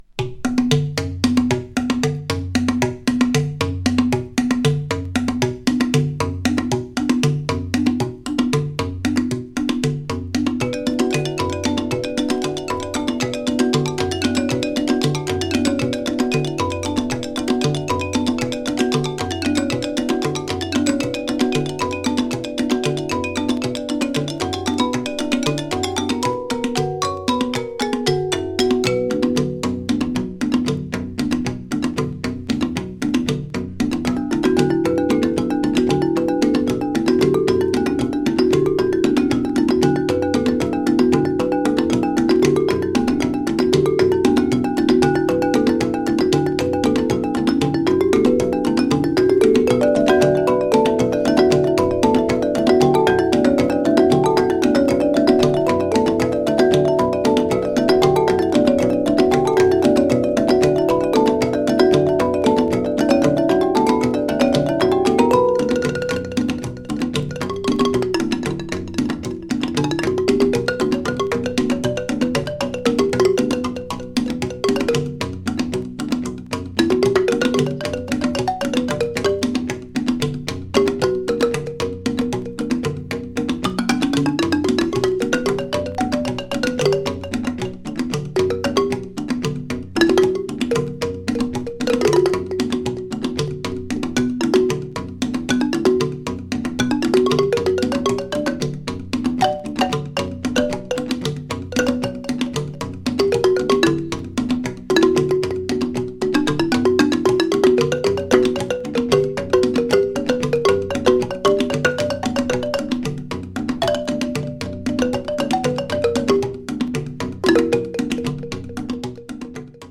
心地良い打楽器サウンドが盛りだくさん。'
盤もキレイですが、若干チリ・パチノイズ入る箇所あります。
Brazil Experimental